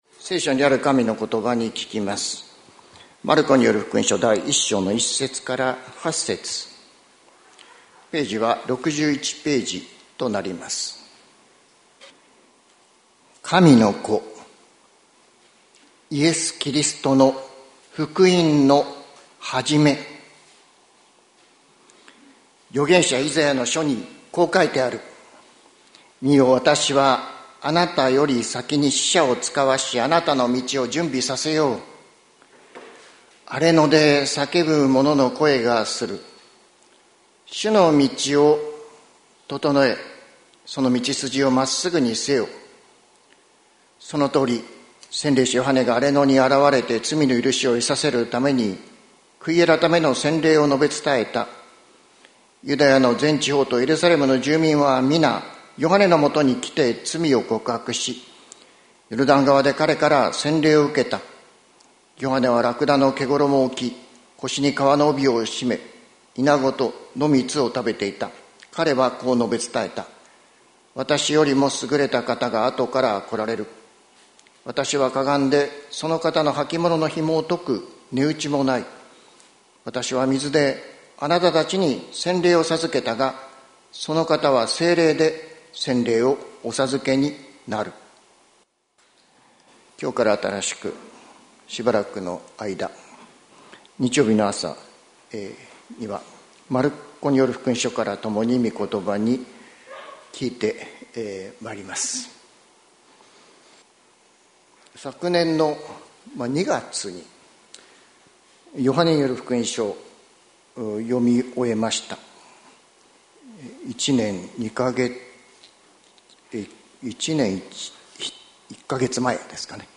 2025年03月09日朝の礼拝「大きな喜び、始まる」関キリスト教会
説教アーカイブ。